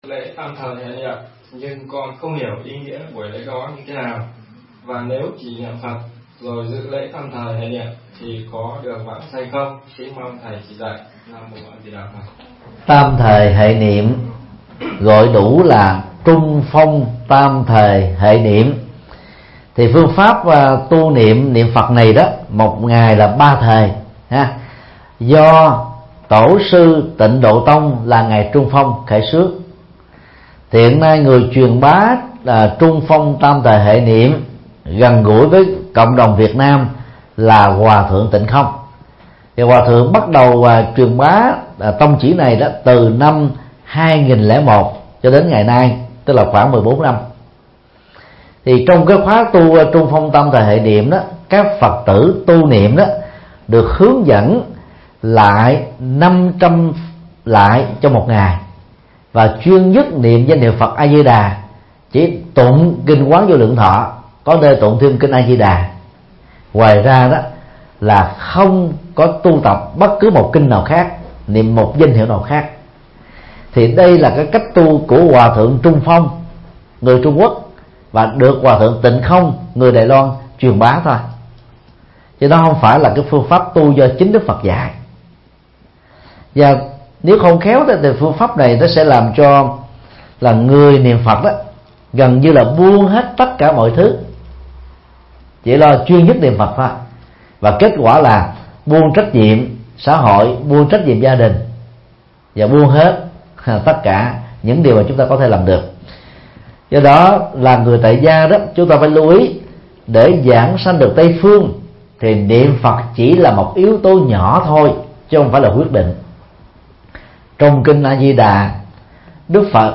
Vấn đáp: Tam thời hệ niệm